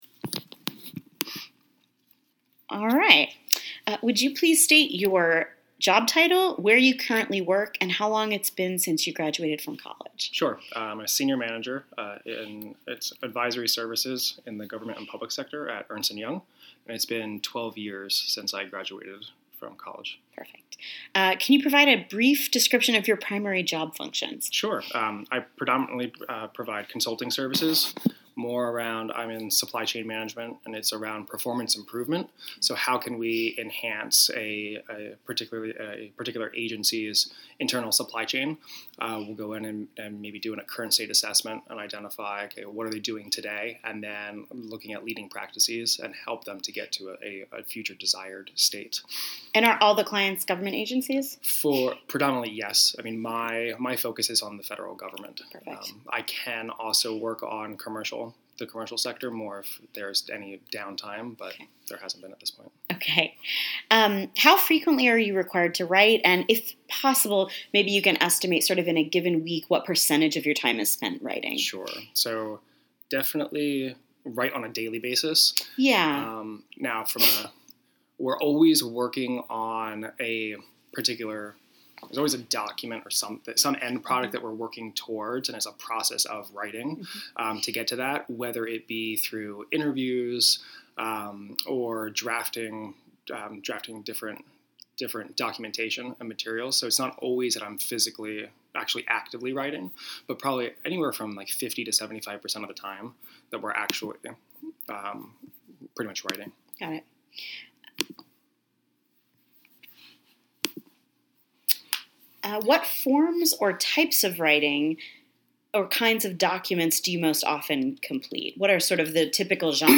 Date of Interview: February 25th, 2017